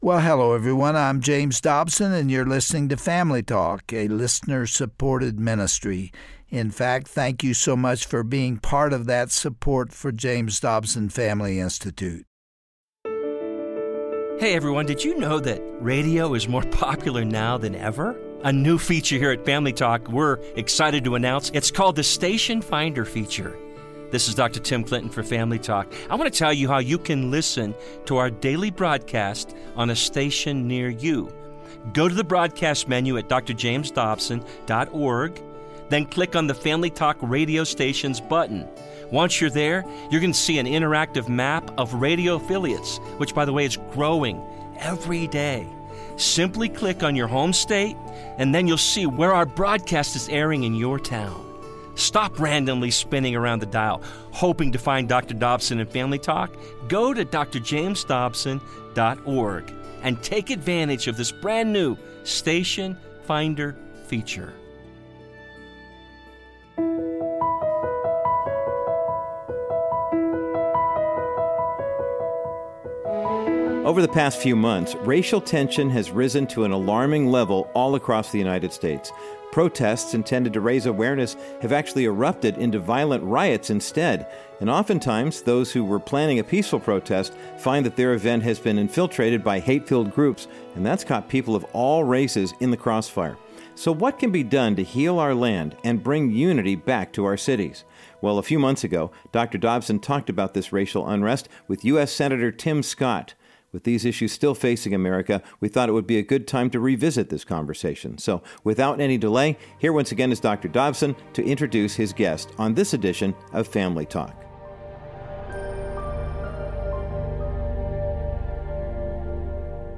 U.S. Senator Tim Scott and Dr. James Dobson talk by phone about the public outcry over Mr. Floyd's death. They address the growing social unrest, as well as condemn the violence, and share a hopeful message of healing.